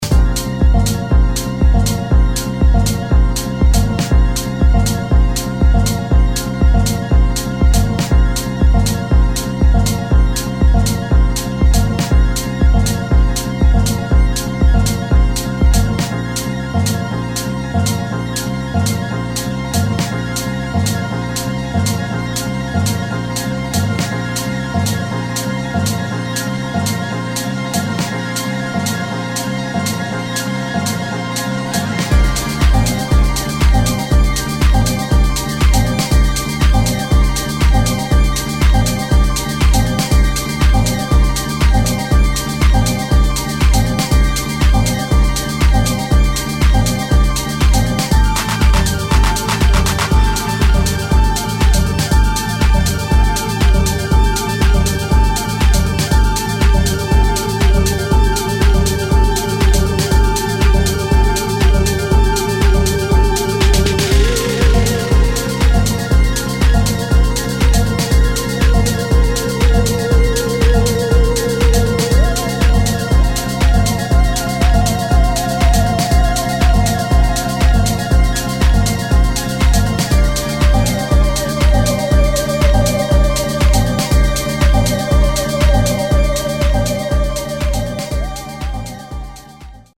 [ TECHNO / DEEP HOUSE ]